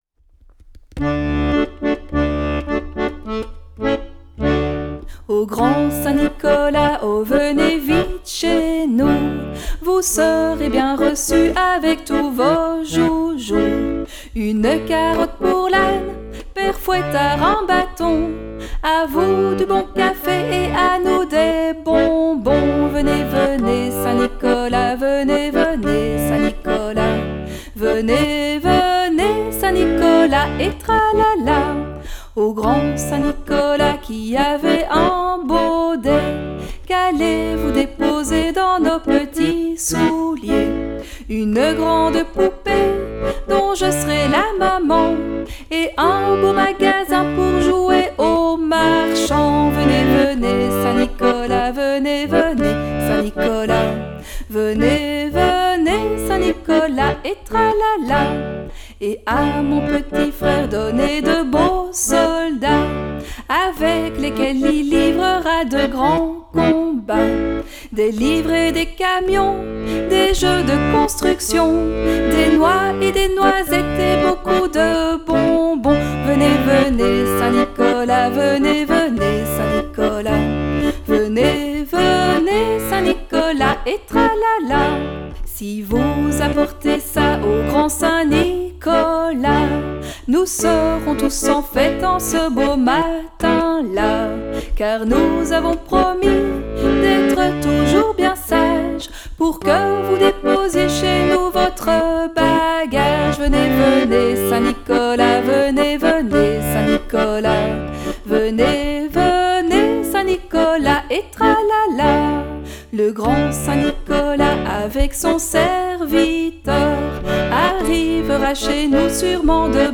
chant
accordéon